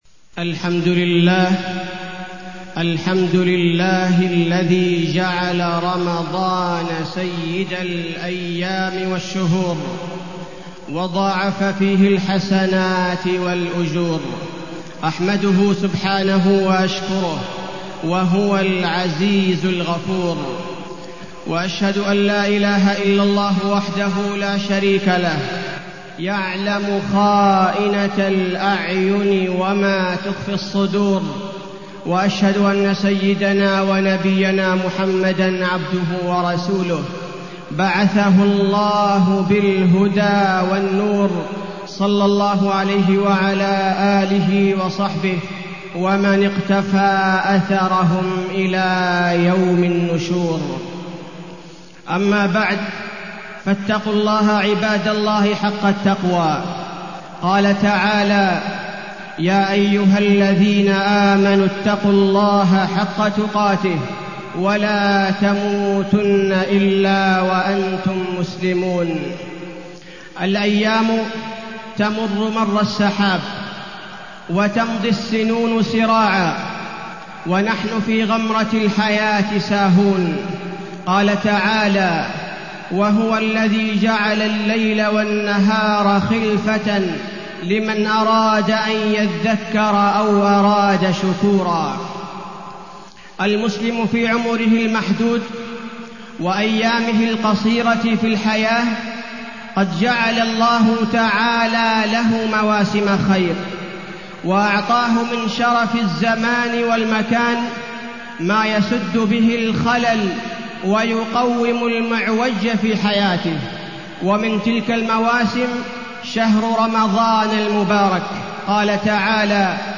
تاريخ النشر ٢ رمضان ١٤٢٠ هـ المكان: المسجد النبوي الشيخ: فضيلة الشيخ عبدالباري الثبيتي فضيلة الشيخ عبدالباري الثبيتي شهر رمضان والشيشان